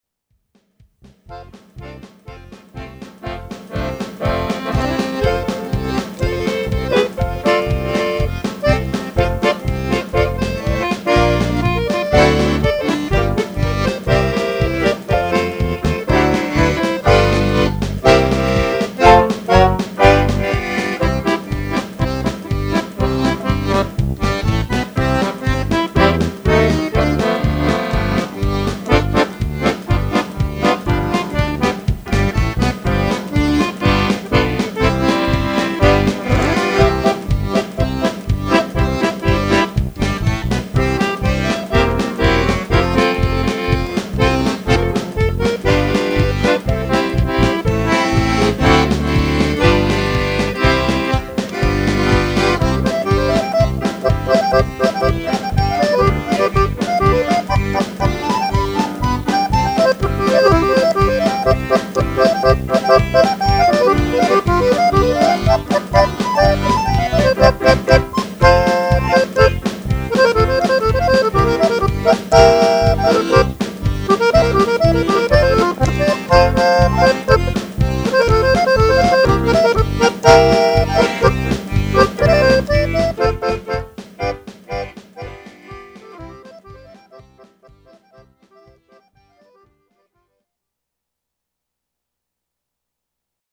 Accordian #1